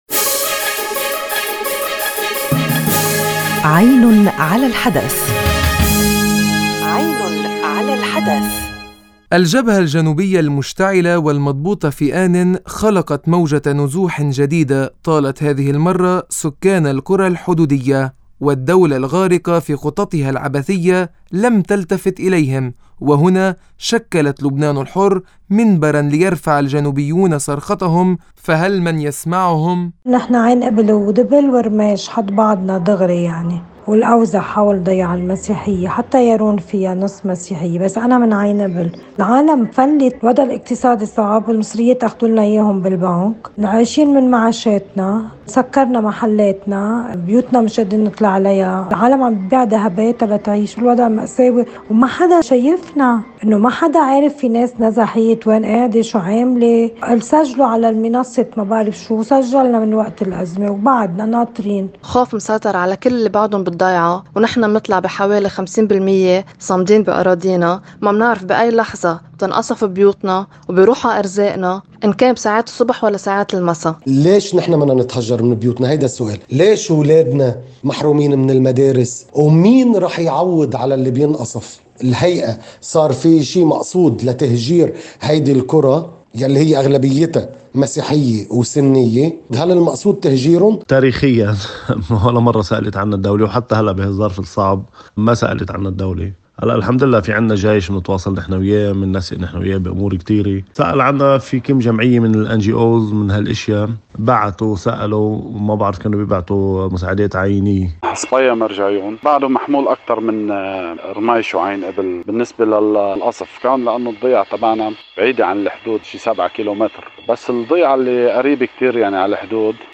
التقرير